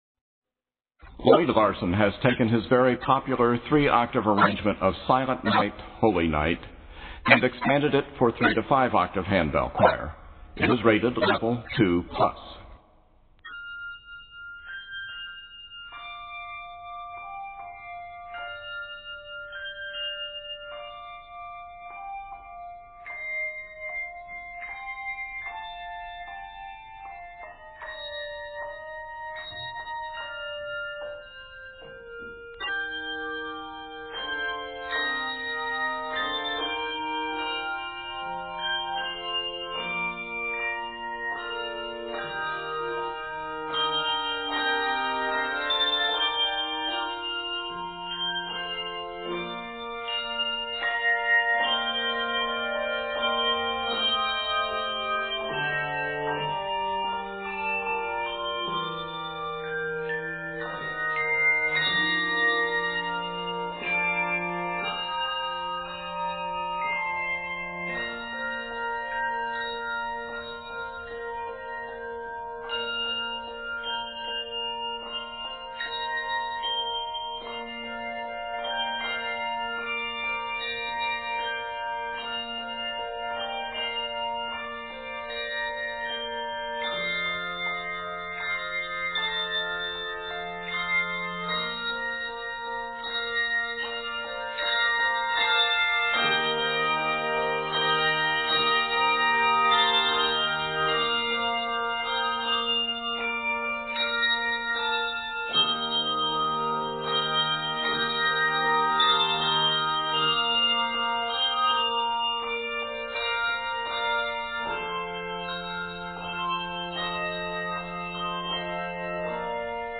carol arrangement
Octaves: 3-5